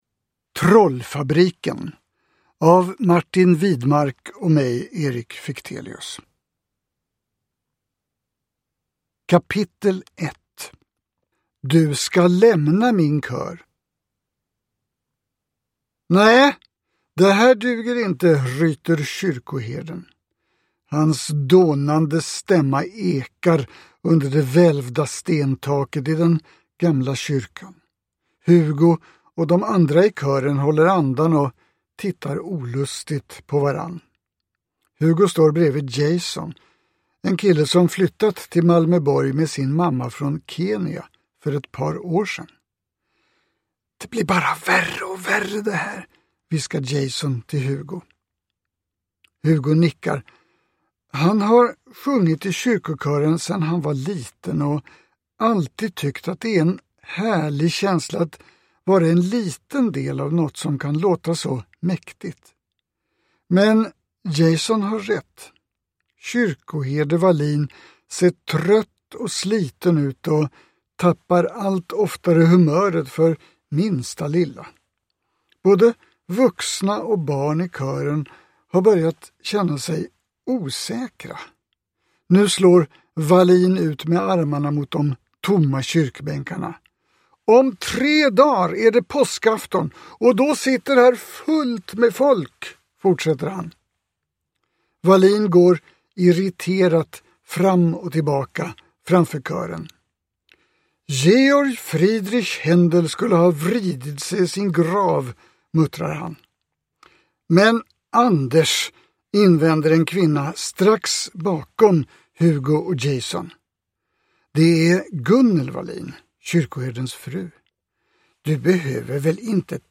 Uppläsare: Erik Fichtelius
Ljudbok